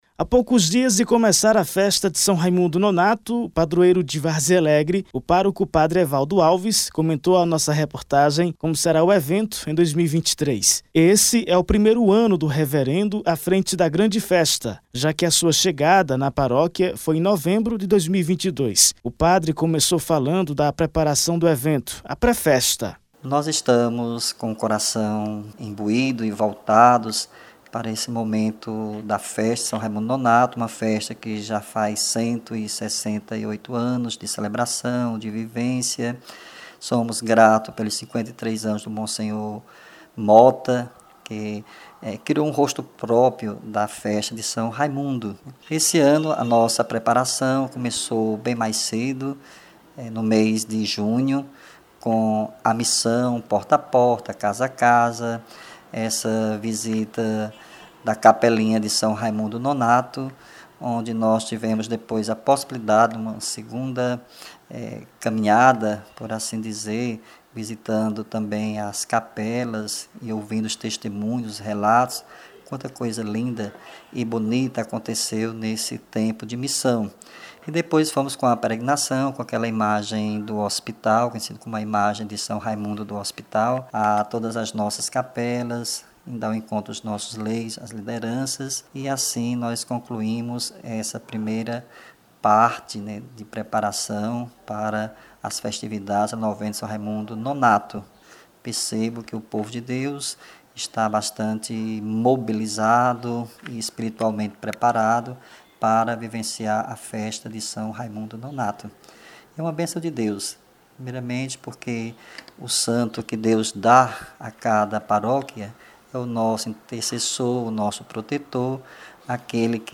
Acompanhe o áudio da reportagem que foi exibida na Rádio Cultura FM 96.3: